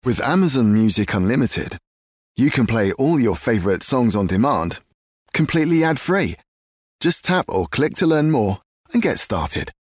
Ankündigungen
Ich habe zu Hause ein eigenes Aufnahmestudio, in dem ich an allen möglichen Audioprojekten arbeite.
Mein freundlicher und bodenständiger Ton, der zwischen den tiefen und mittleren Tönen liegt, verbindet das Publikum auf eine Art und Weise, die sowohl fesselnd als auch zugänglich ist.
BaritonBassTiefNiedrig